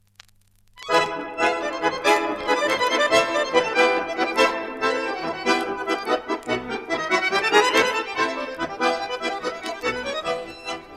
В приложенной аудиозаписи дан фрагмент мелодии для танца "Яблочко".